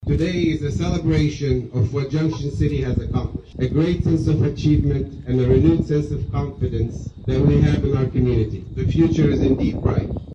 USD 475 Board President Dr. Anwar Khoury spoke at the dedication ceremony.